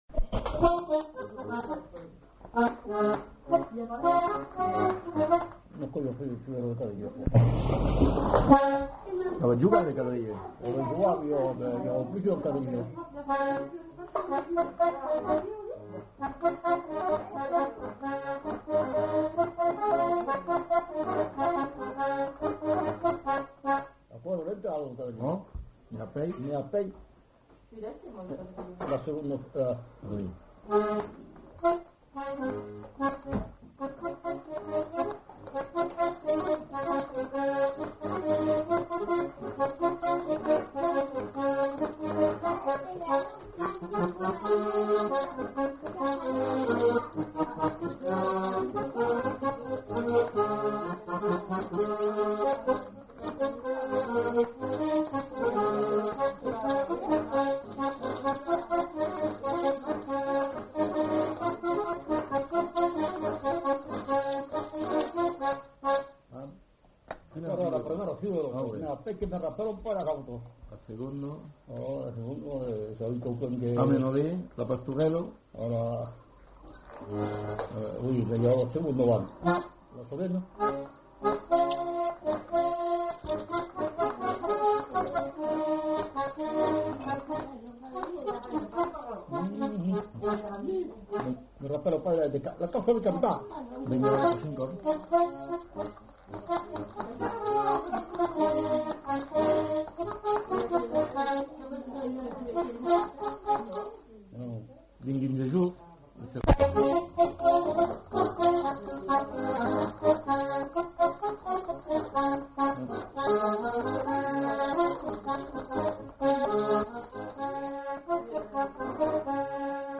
Aire culturelle : Savès
Genre : morceau instrumental
Instrument de musique : accordéon diatonique
Danse : quadrille